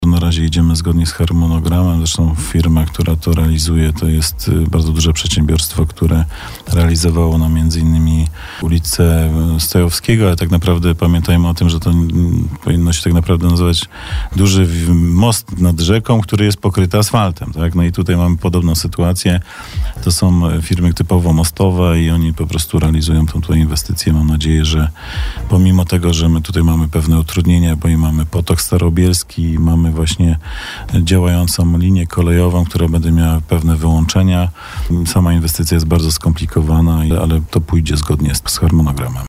Oczywiście, reagujemy też na sygnały, które przychodzą z zewnątrz, od kierowców – mówił na naszej antenie Przemysław Kamiński, wiceprezydent Bielska-Białej.